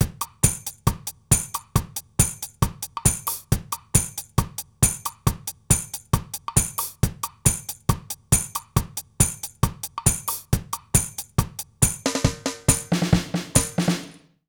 British REGGAE Loop 143BPM.wav